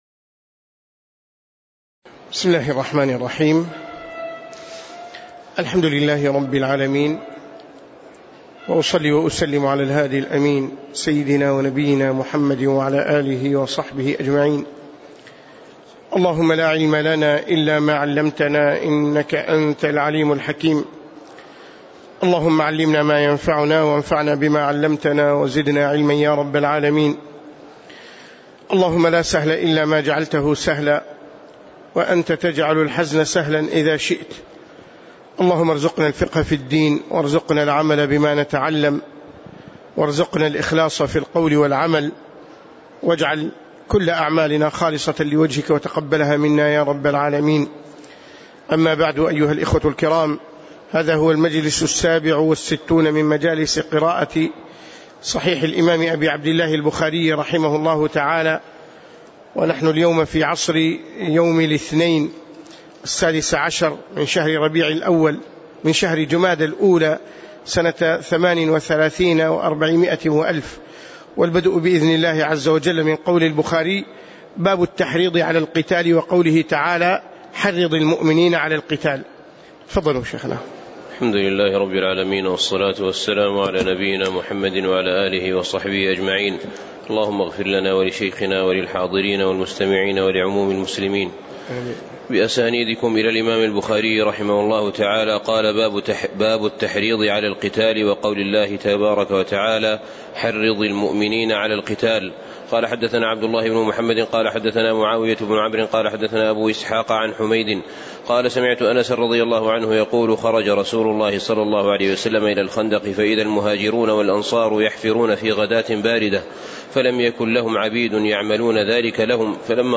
تاريخ النشر ١٦ جمادى الأولى ١٤٣٨ هـ المكان: المسجد النبوي الشيخ